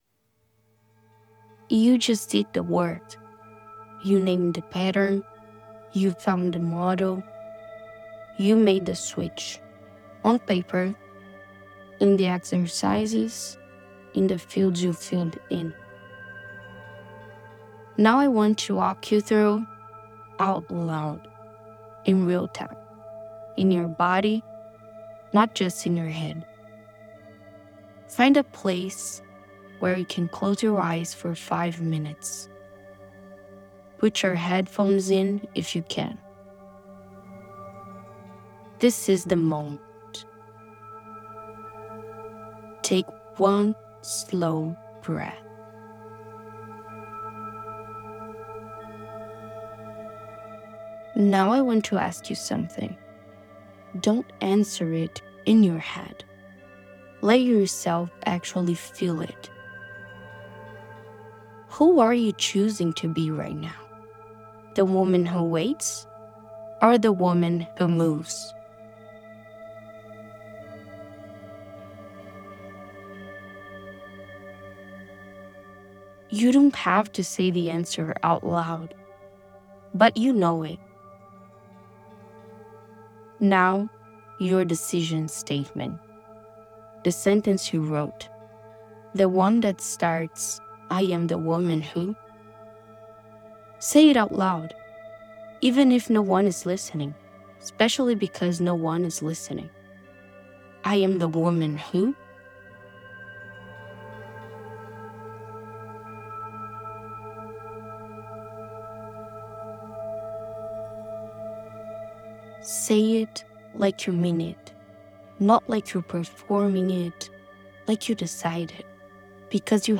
The founder guides you through every step — the question, the statement, the action — out loud, in real time.